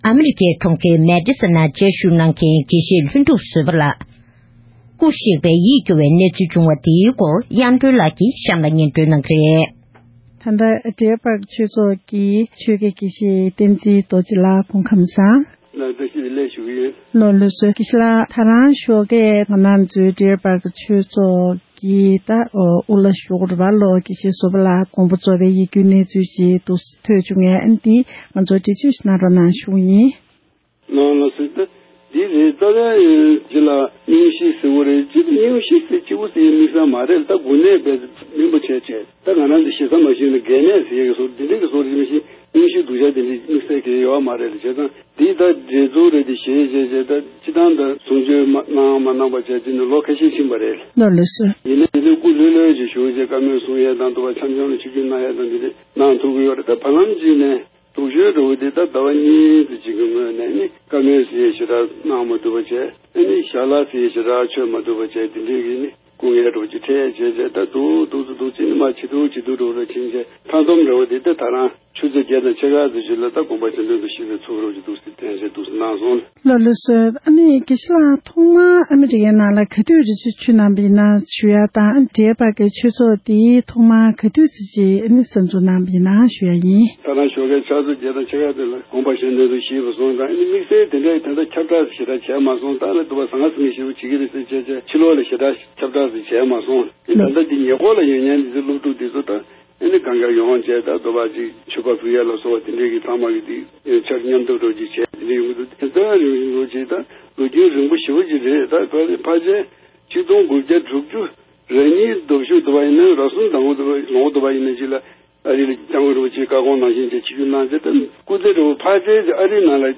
སྒྲ་ལྡན་གསར་འགྱུར། སྒྲ་ཕབ་ལེན།
གནས་འདྲི་ཞུས་པ་ཞིག